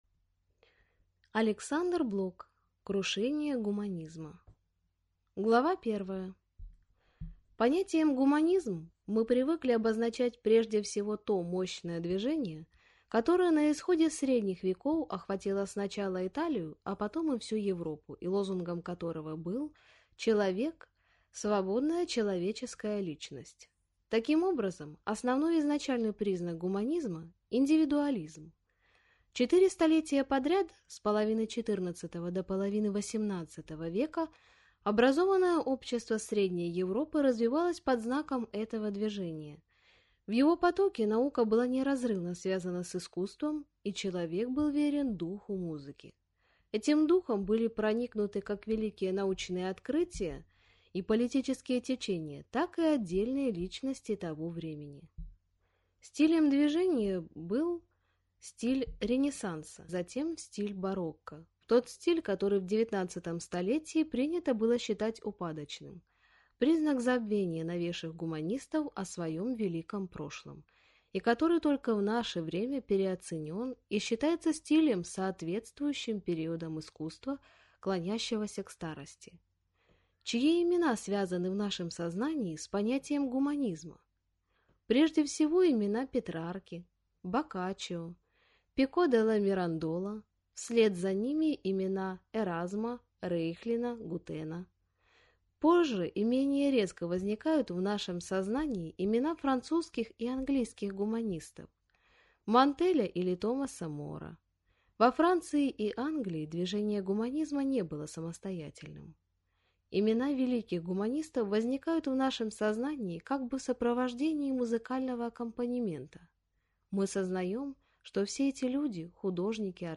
Аудиокнига Крушение гуманизма | Библиотека аудиокниг